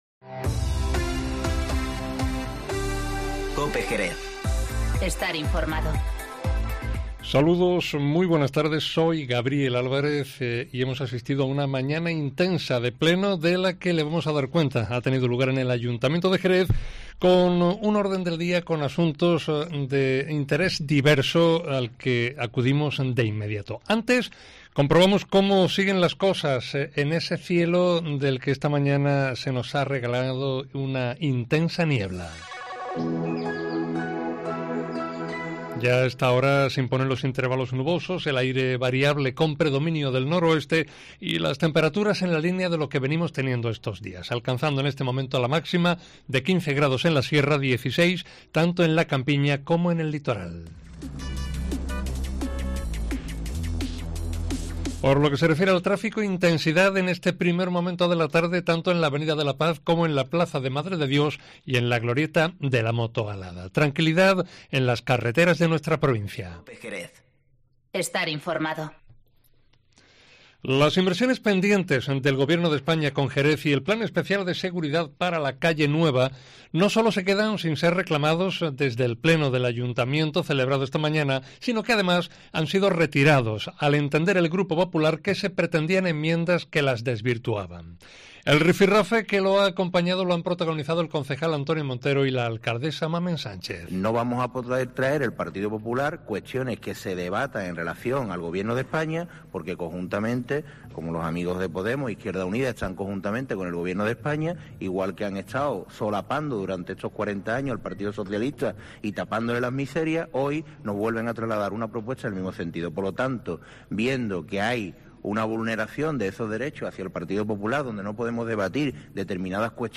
Informativo Mediodía COPE en Jerez 30-01-20